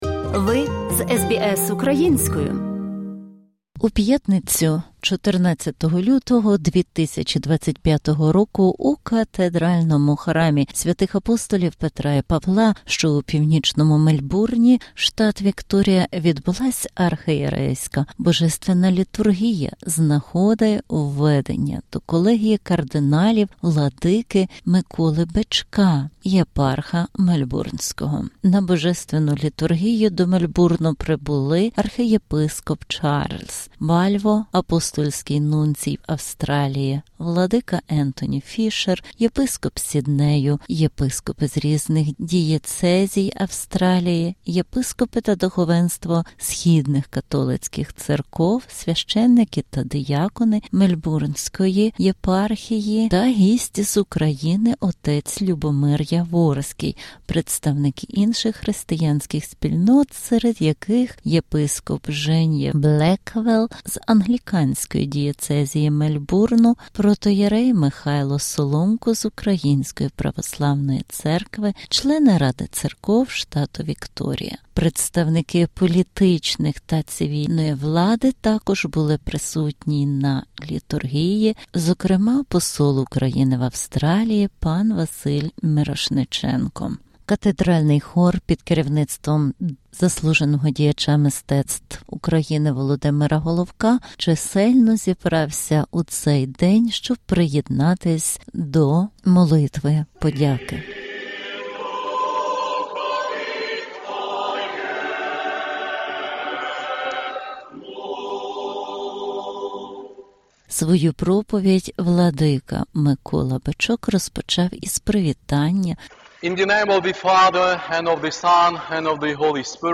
Репортаж документує Архиєрейську Божественну Літургію, яка відбулася в Катедрі Свв. Верх. Апп. Петра і Павла Мельбурнської єпархії УГКЦ в Австралії, Новій Зеландії та країнах Океанії 14 лютого 2025 з нагоди введення до Колегії кардиналів владики Миколи Бичка, Єпарха Мельбурнського.